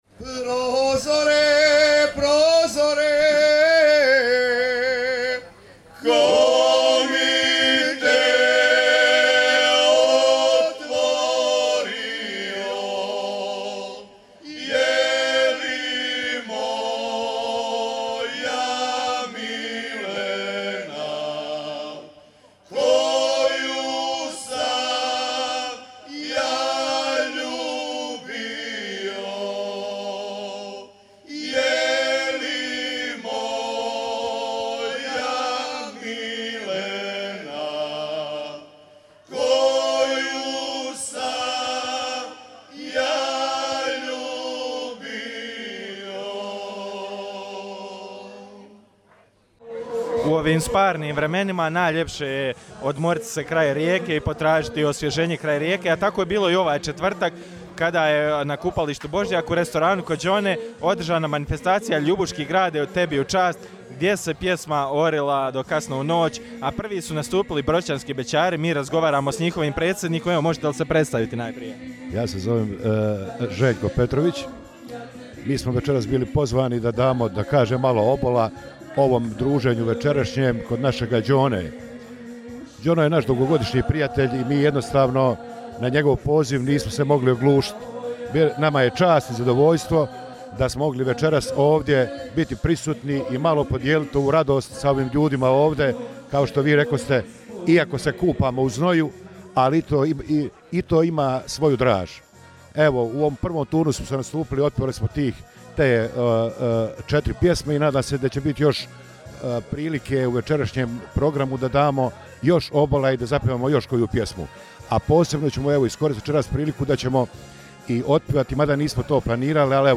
Na kupalištu Božjak, u restoranu ‘Kod Đone’ pjevalo se i sinoć „sve u četiri,“ orili su se bećarci od broćanskih, pa studenačkih sve do dubravskih.